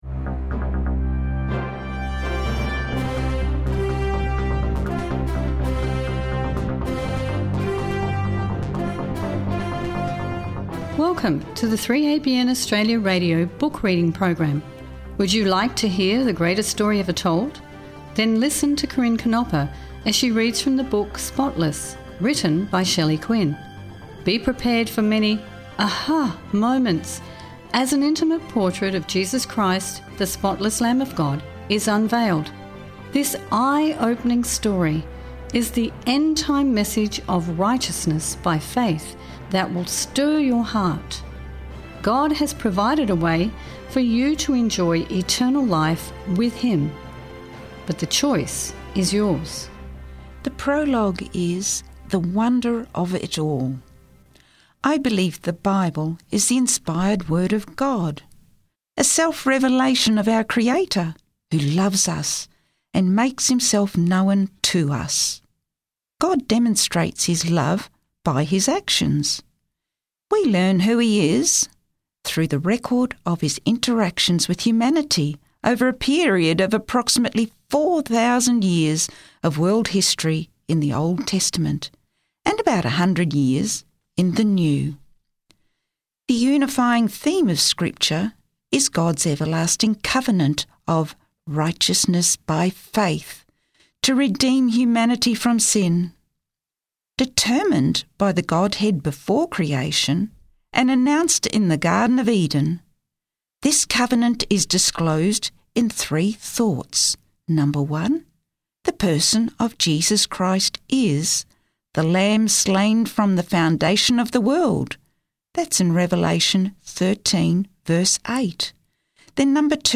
Book Reading - Spotless